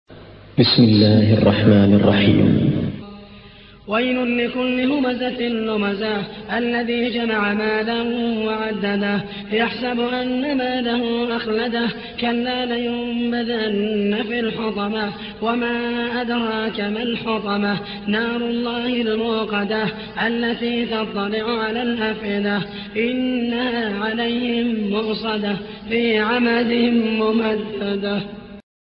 Récitation par Mohamed El Mohaisany